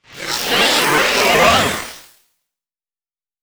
Techno / Voice / VOICEFX208_TEKNO_140_X_SC2(R).wav